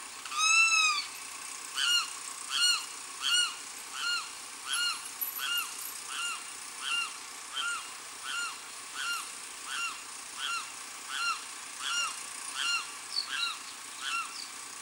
Carão (Aramus guarauna)
Nome em Inglês: Limpkin
Fase da vida: Adulto
Localidade ou área protegida: Reserva Ecológica Costanera Sur (RECS)
Condição: Selvagem
Certeza: Fotografado, Gravado Vocal
voz-carau.mp3